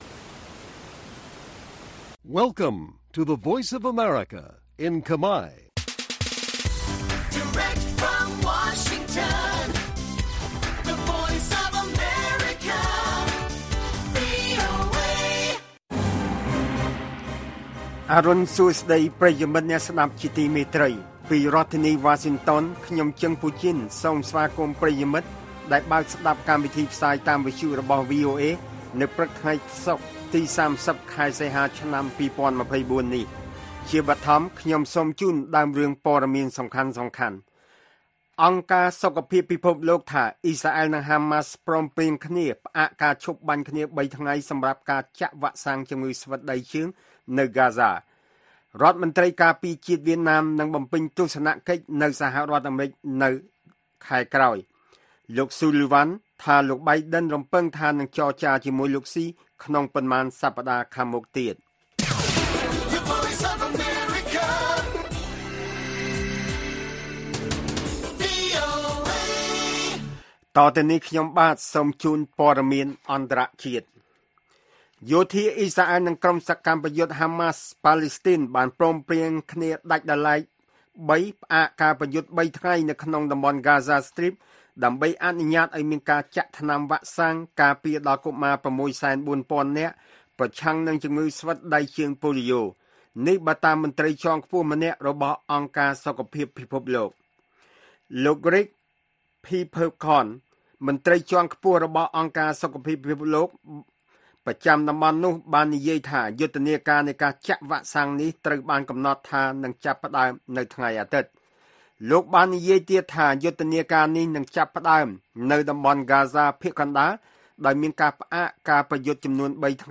ព័ត៌មានពេលព្រឹក៣០ សីហា៖ បទសម្ភាសន៍ VOA